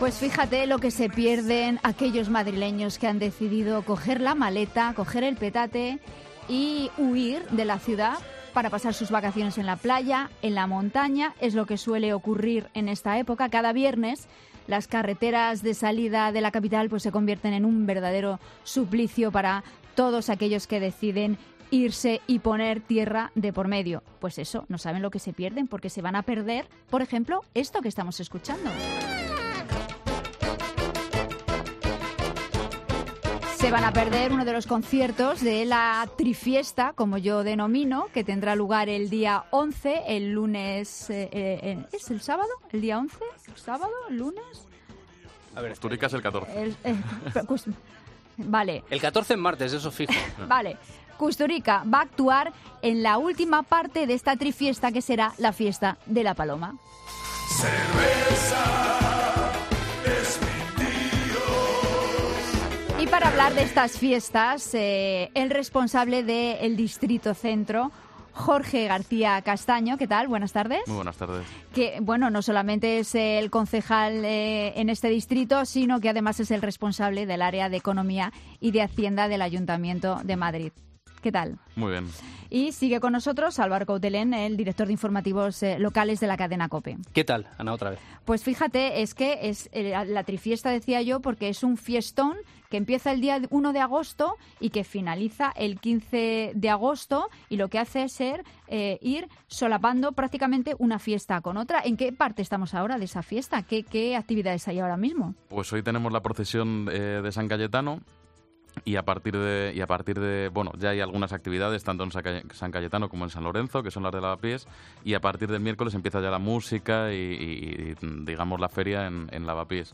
Este martes hemos recibido en COPE Más Madrid la visita de Jorge García Castaño , responsable del Área de Economía y Hacienda y de la Junta Municipal de Centro del Ayuntamiento de Madrid. Son muchos los temas a tratar sobre este distrito: las fiestas que se celebran durante estos días -San Cayetano, San Lorenzo y La Paloma-, las obras de la Gran Vía, la nueva APR Madrid Central, etc.